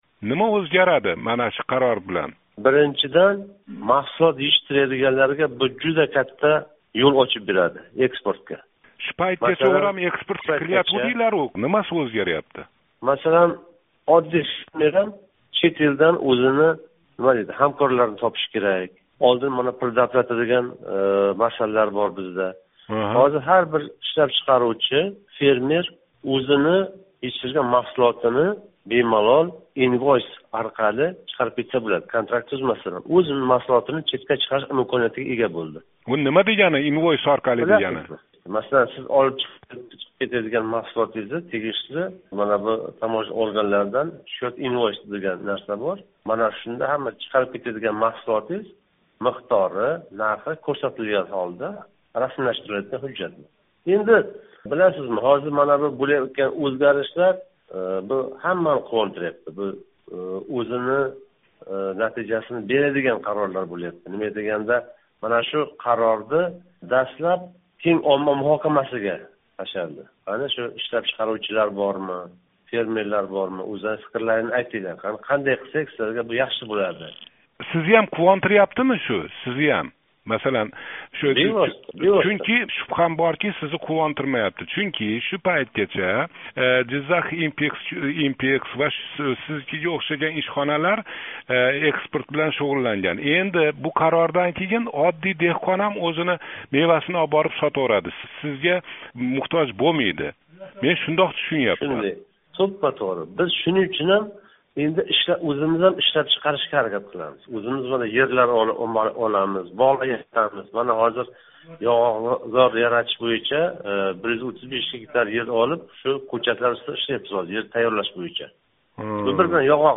Жиззахлик экспортчи билан суҳбат